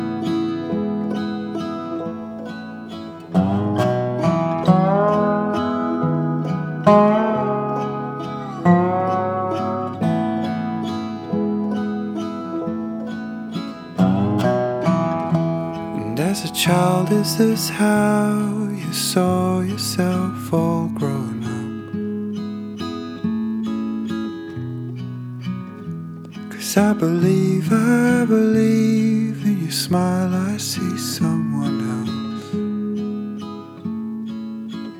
Жанр: Фолк-рок
# Traditional Folk